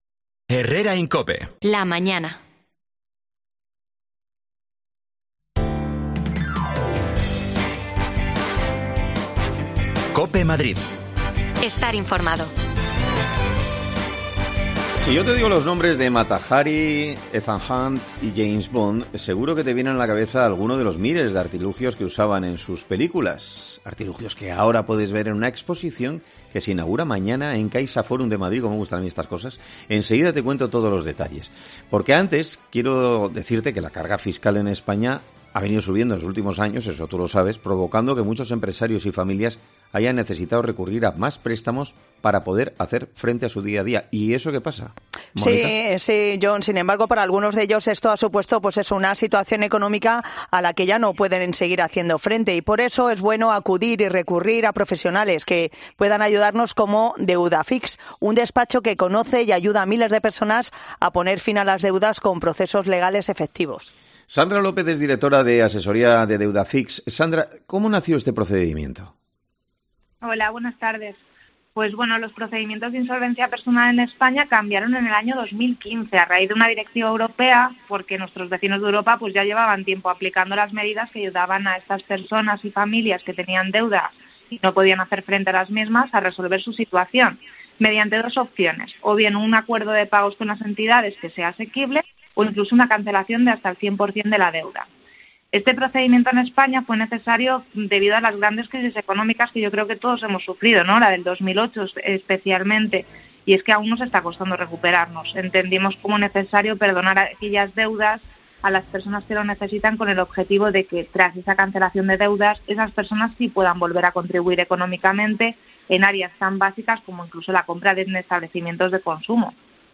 Escucha ya las desconexiones locales de Madrid de Herrera en COPE en Madrid y Mediodía COPE en Madrid .
Las desconexiones locales de Madrid son espacios de 10 minutos de duración que se emiten en COPE , de lunes a viernes.